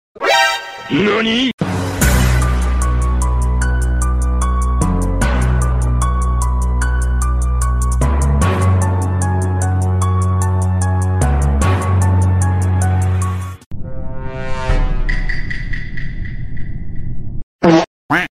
funny meme sounds